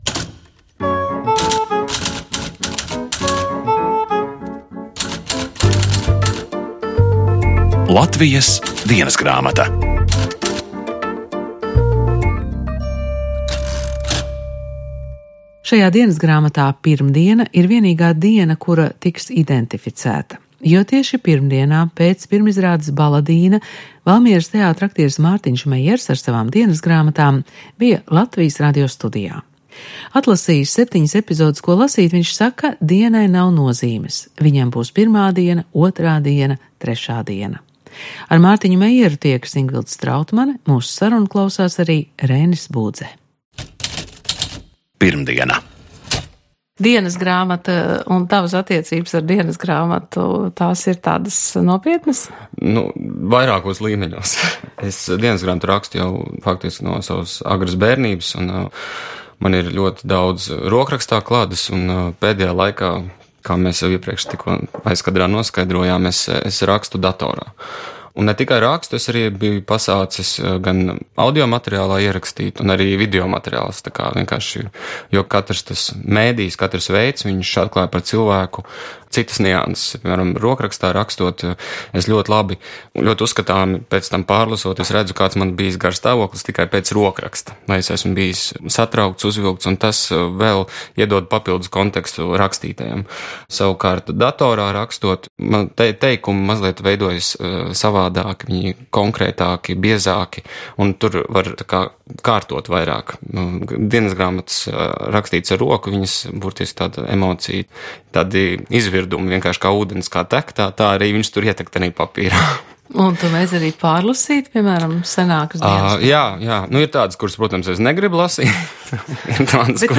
paņēma savas dienasgrāmatas un atbrauca uz Radio studiju. Izrādās, viņš raksta dienasgrāmatas jau sen.